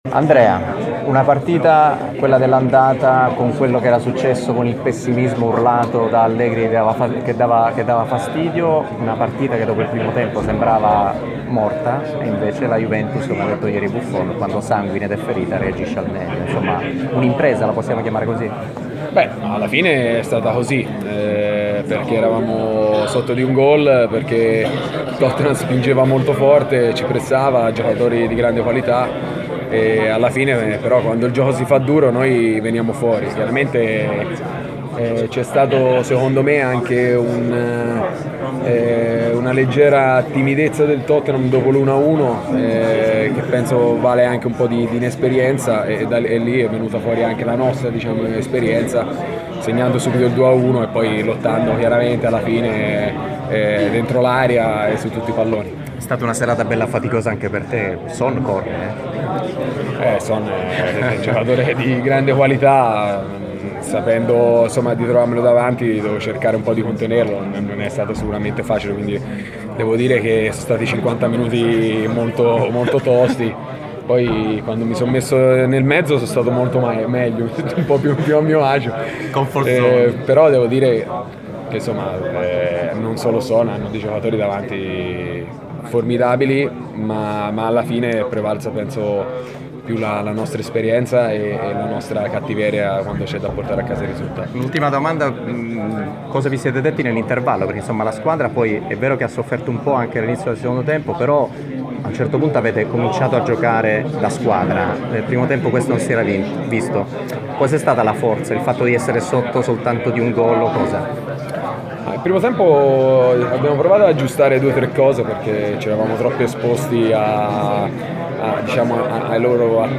Andrea Barzagli, difensore della Juventus
nel post Tottenham-Juventus 1-2